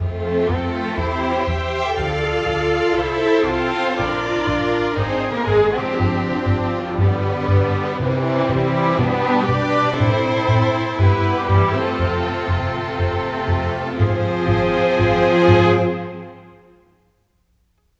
Rock-Pop 11.wav